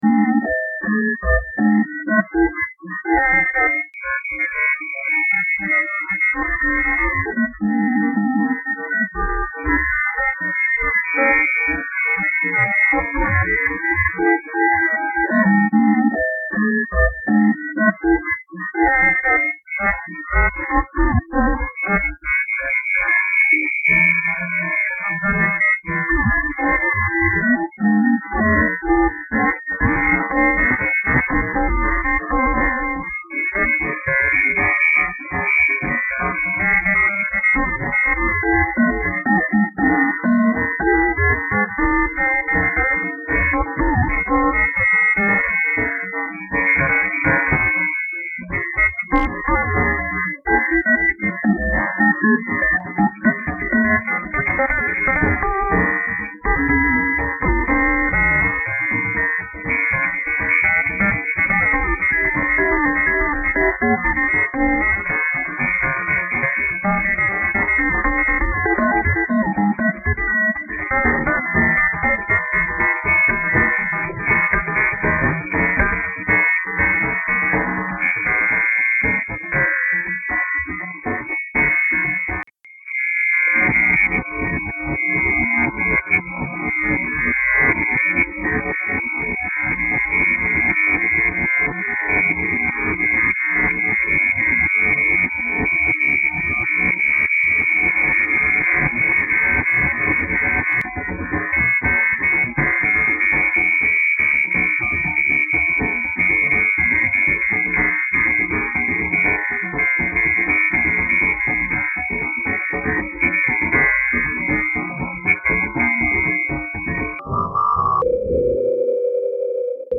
This is a recording of experimental acoustic guitar with a ton of filters and effects.
It is simply an acoustic guitar recording of some chord changes- kind of what I usually do- but is about as heavily modified and filtered as possible.